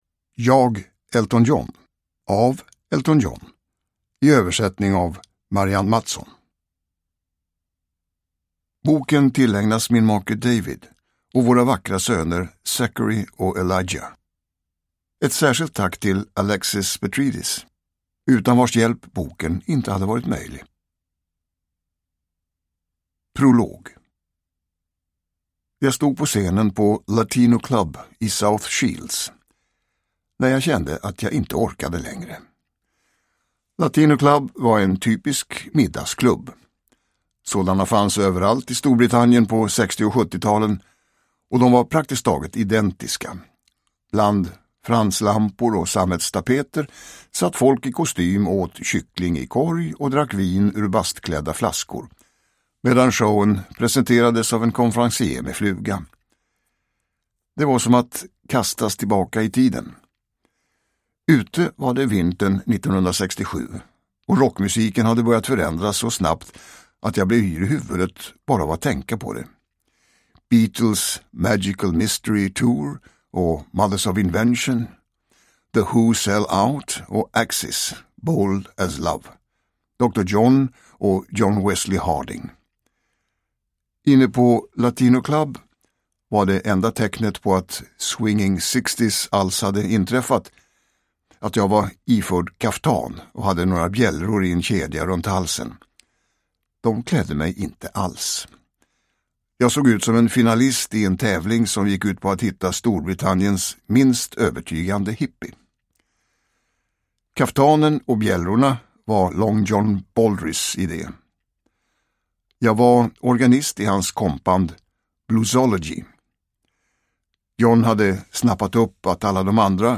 Jag – Ljudbok – Laddas ner
Uppläsare: Tomas Bolme